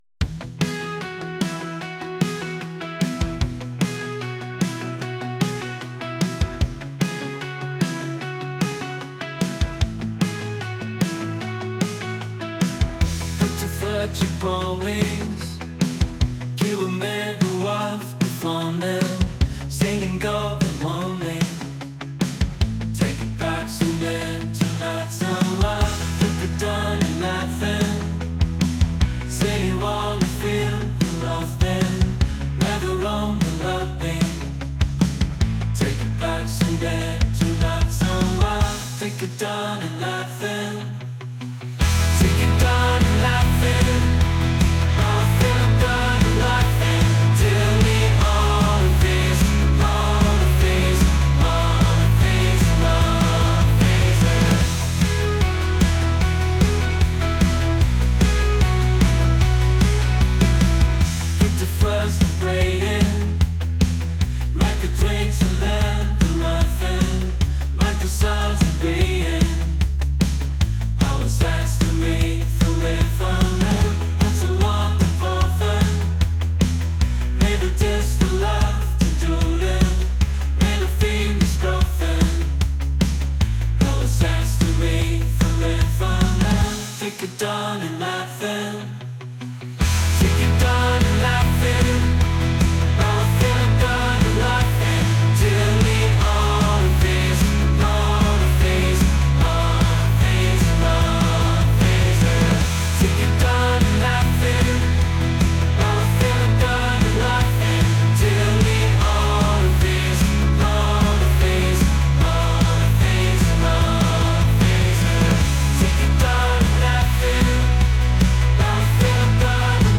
pop | acoustic | folk